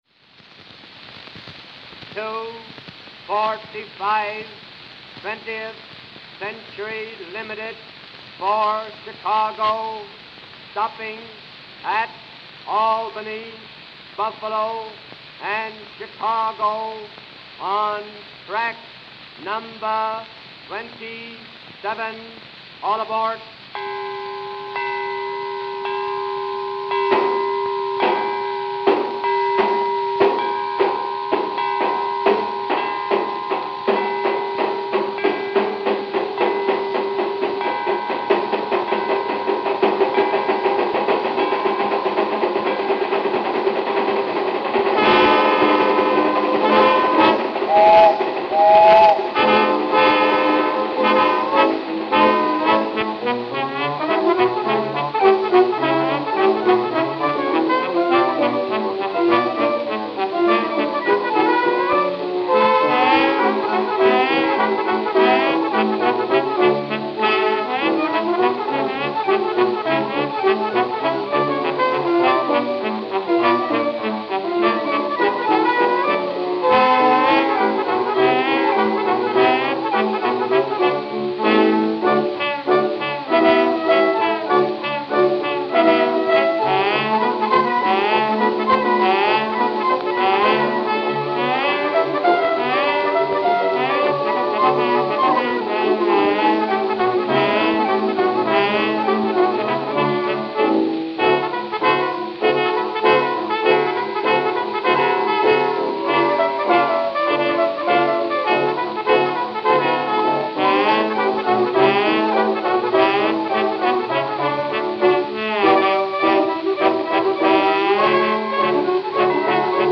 I even only use the left channel when transferring these.